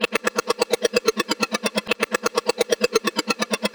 VEH1 Fx Loops 128 BPM
VEH1 FX Loop - 06.wav